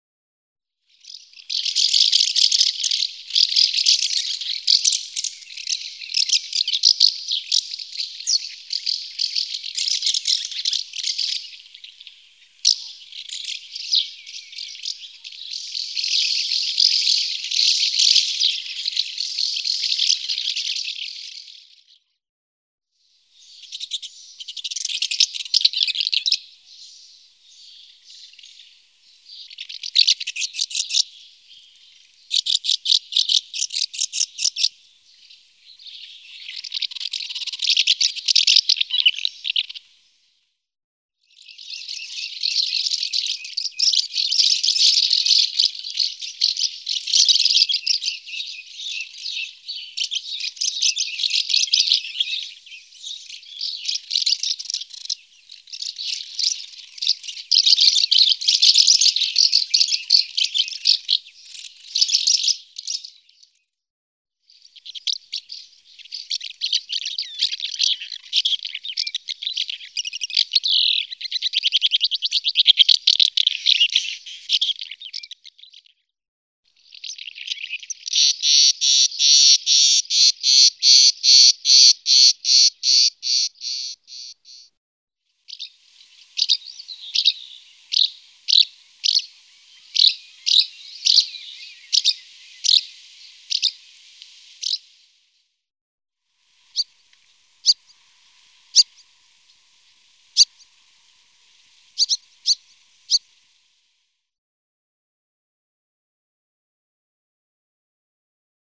Balestruccio (Delichon urbicum)
balestruccio-No39-copia.mp3